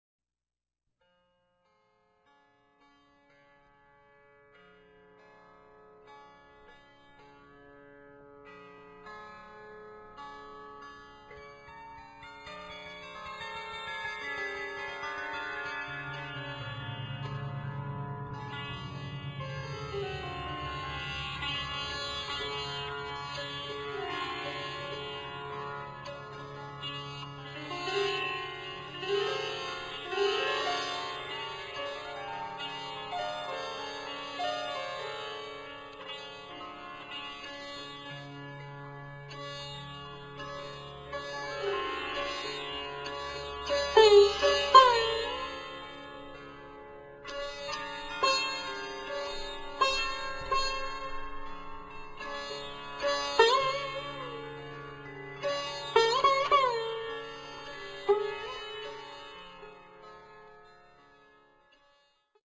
Ceux enregistrés sur cet album viennent de l’Inde ancienne.